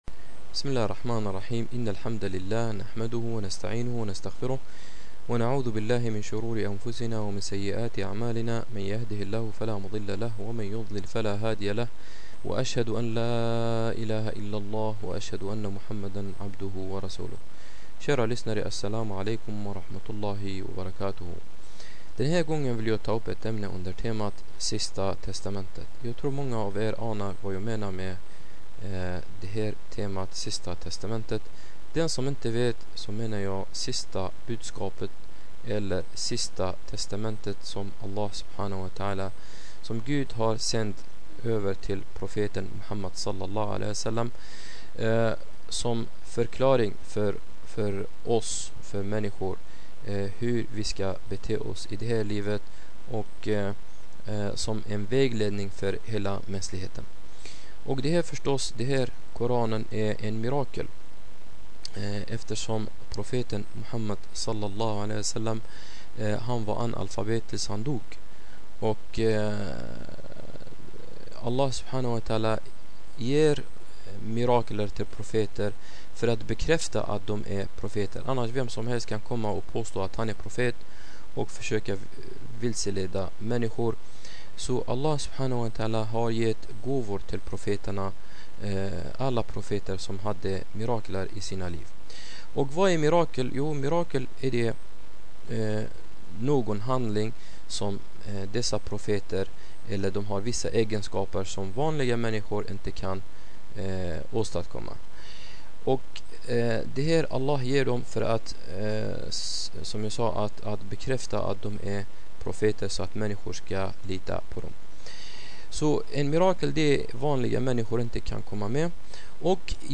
Ett föredrag om Koranens budskap